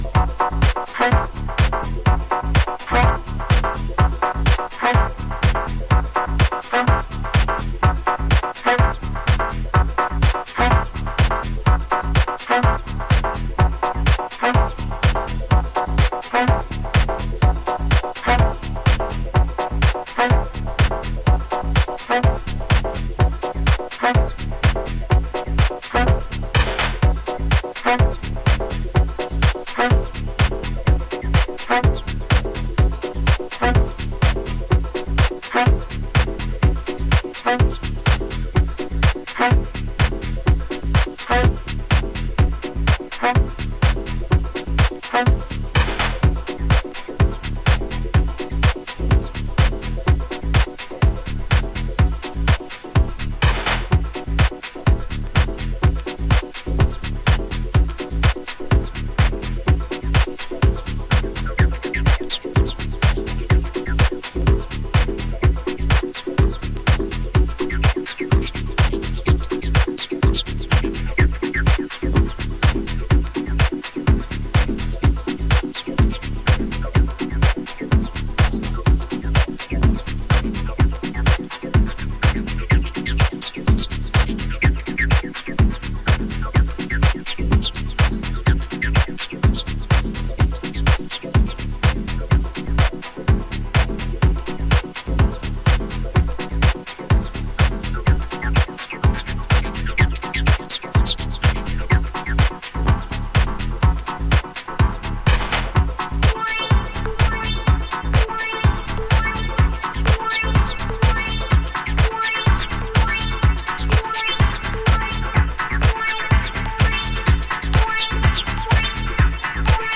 タメの効いたグルーヴでミニマルに展開していきます。使っているベースやシンセが90's 心地。アシッディーな